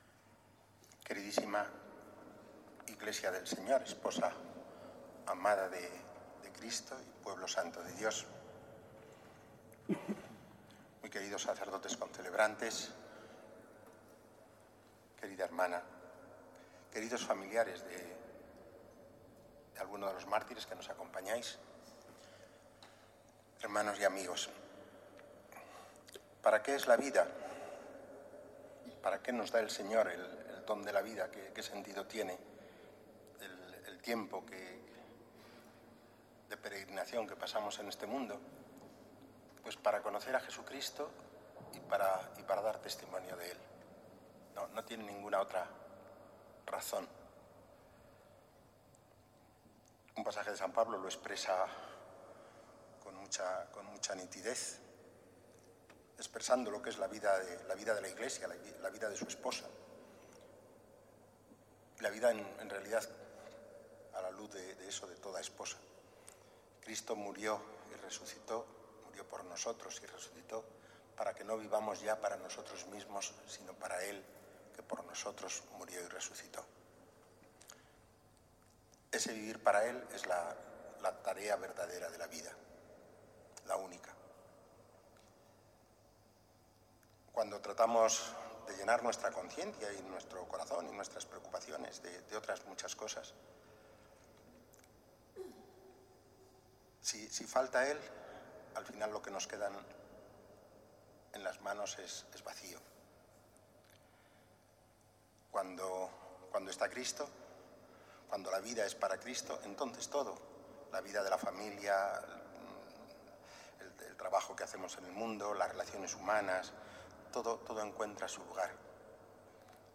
Homilía de Mons. Javier Martínez en la Eucaristía de acogida de los restos y reliquias de los nuevos beatos granadinos, beatificados el 25 de marzo en Aguadulce (Almería), en la Causa José Álvarez-Benavides y de la Torre y 114 compañeros mártires de Cristo en la persecución religiosa en España en el siglo XX.